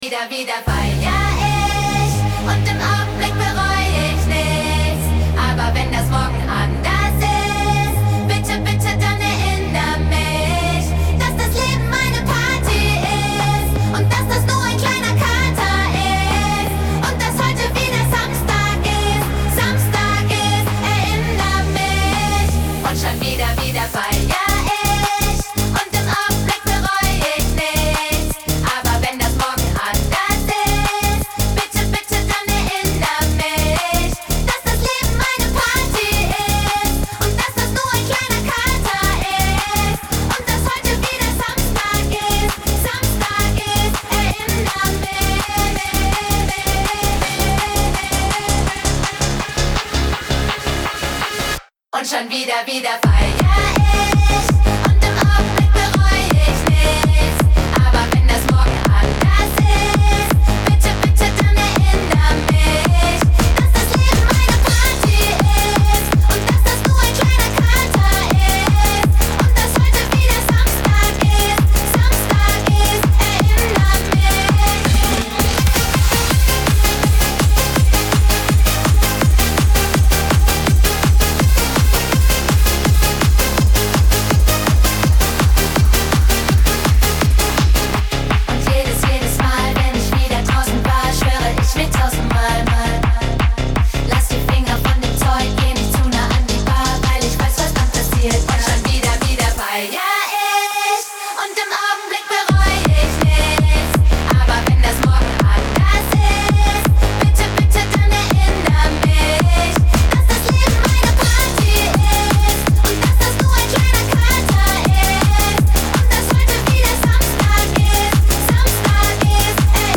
EDM GER